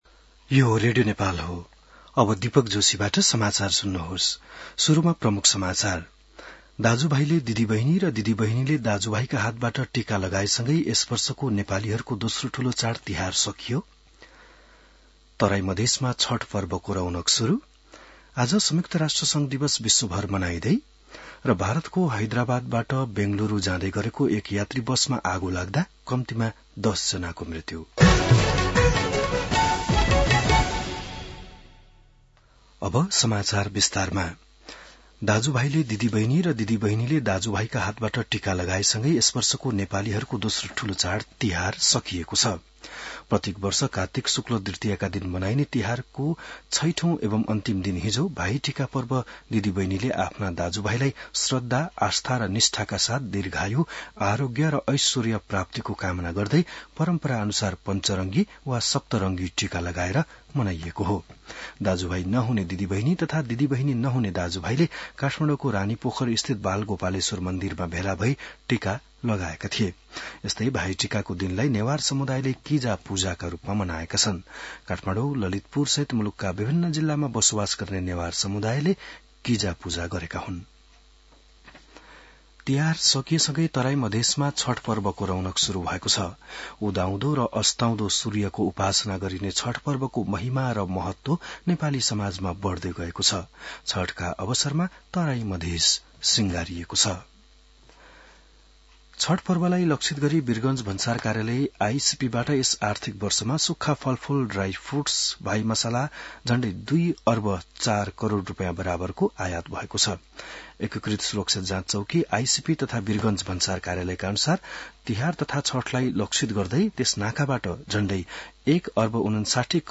बिहान ९ बजेको नेपाली समाचार : ७ कार्तिक , २०८२